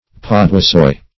Search Result for " paduasoy" : The Collaborative International Dictionary of English v.0.48: Paduasoy \Pad`u*a*soy"\, n. [From Padua, in Italy + F. soie silk; or cf. F. pou-de-soie.] A rich and heavy silk stuff.
paduasoy.mp3